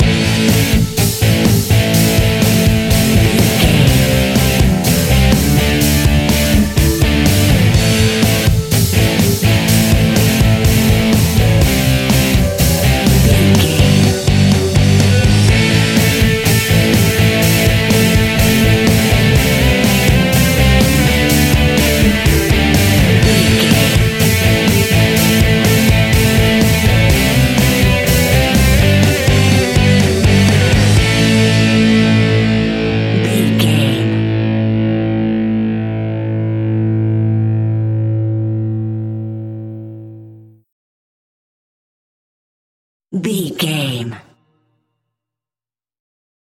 Epic / Action
Fast paced
Aeolian/Minor
hard rock
heavy metal
blues rock
distortion
rock guitars
Rock Bass
Rock Drums
heavy drums
distorted guitars
hammond organ